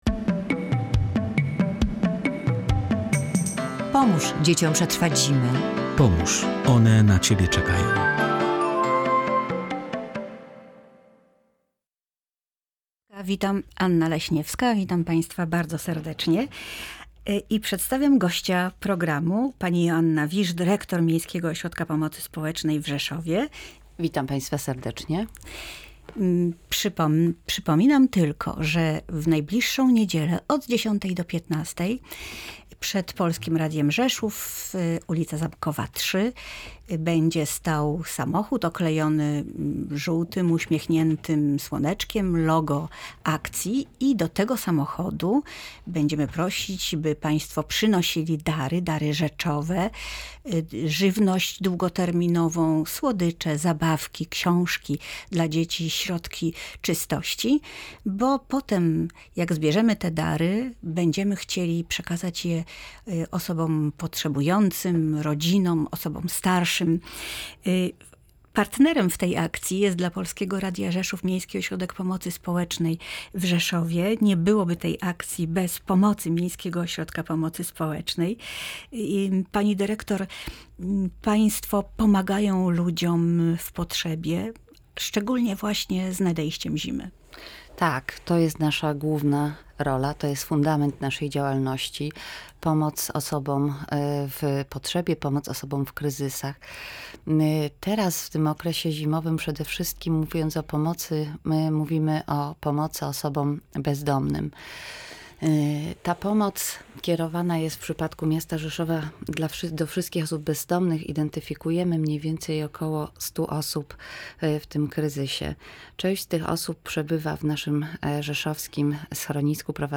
W Tu i Teraz rozmawialiśmy o pomocy potrzebującym w czasie zimy.